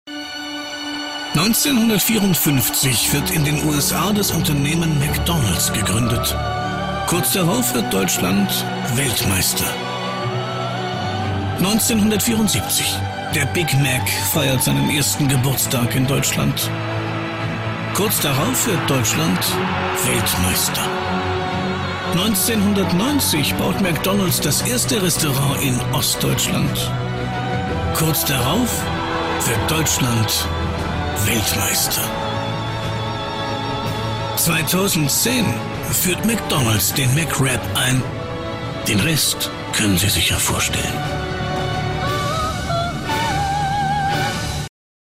Werbung Hochdeutsch (DE)
Bekannte Off-Stimme.